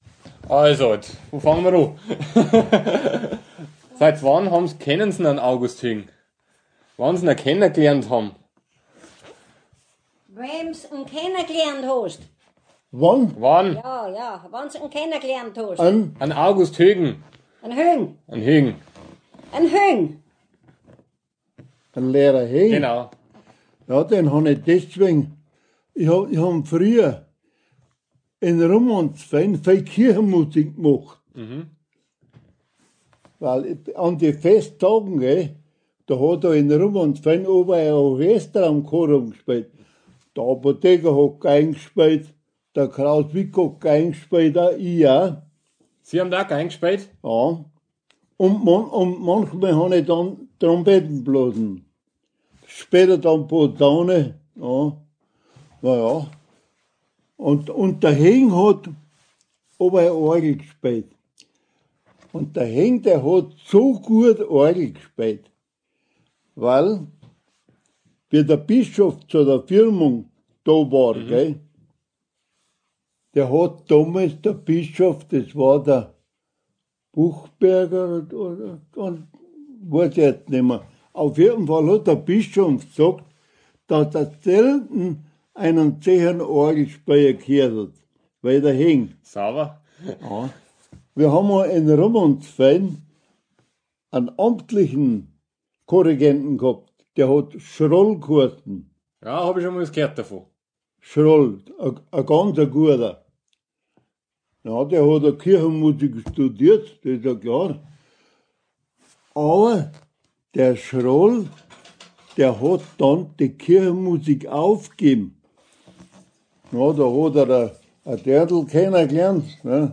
Ort Kaikenried
interview.m4a